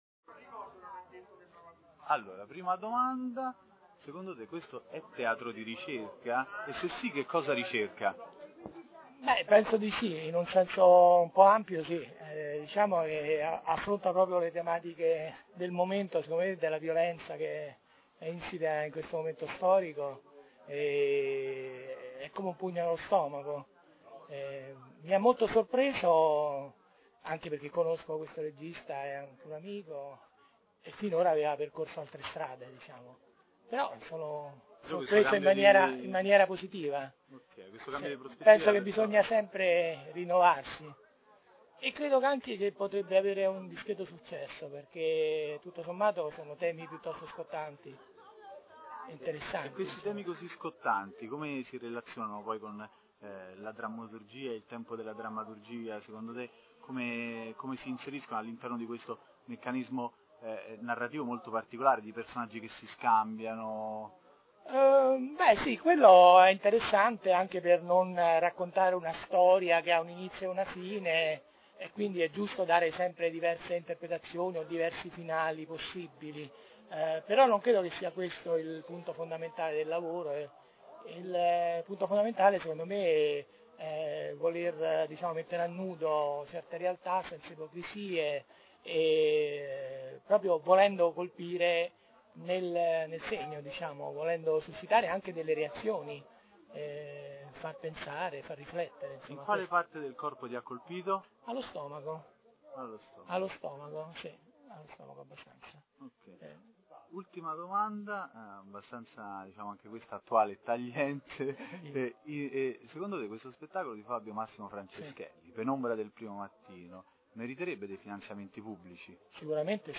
Ogni sera intervisteremo il pubblico porgendogli tre domande sugli spettacoli.
all’uscita di Penombra del primo mattino di Olivieri Ravelli_Teatro